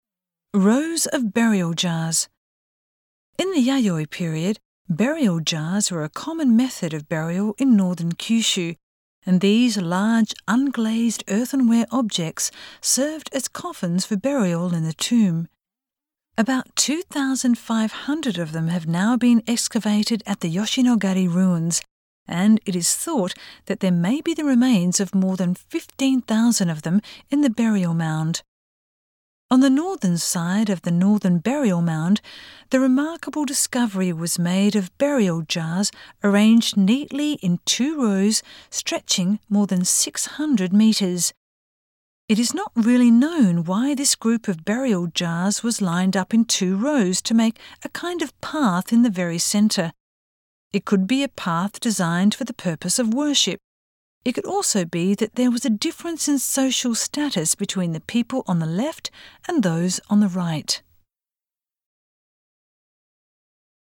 Voice guide